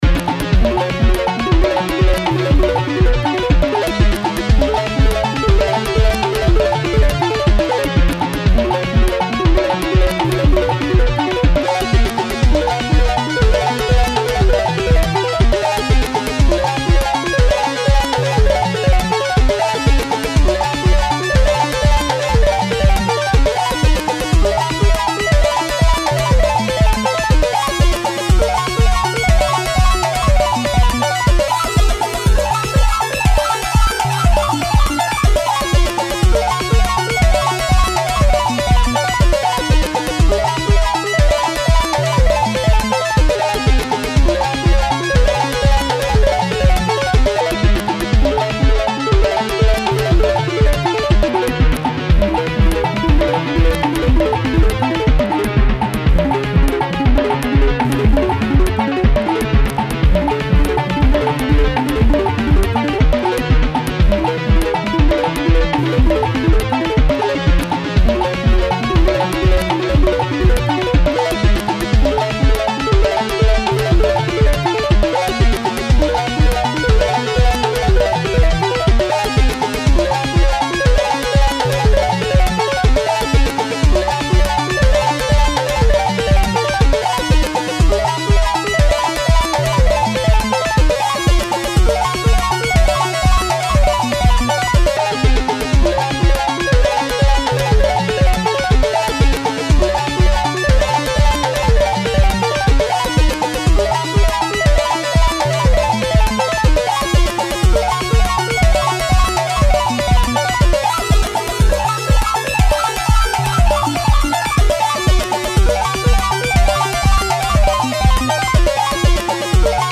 BGM
EDMエレクトロニカファンタジーロング